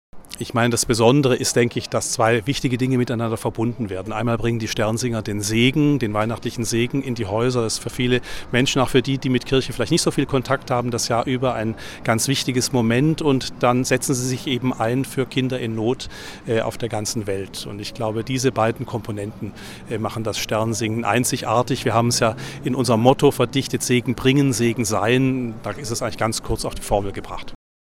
Prälat Dr. Klaus Krämer, Präsident des Kindermissionswerks ‚Die Sternsinger‘